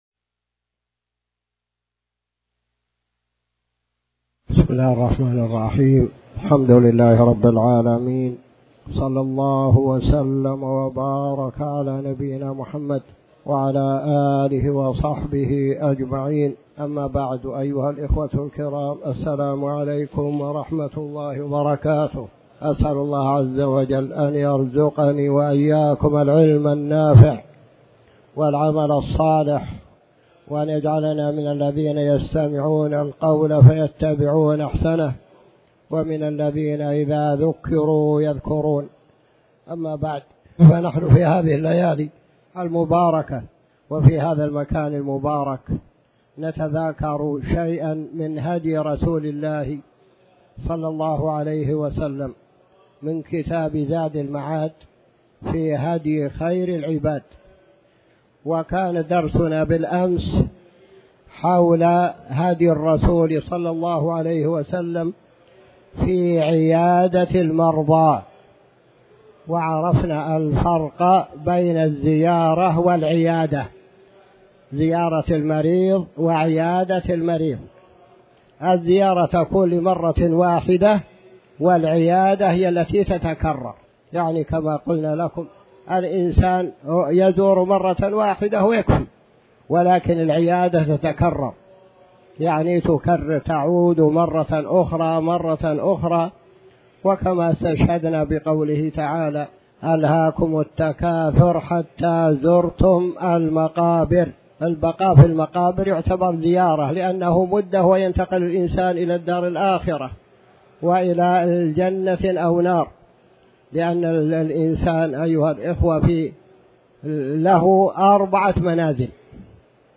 تاريخ النشر ٦ محرم ١٤٤٠ هـ المكان: المسجد الحرام الشيخ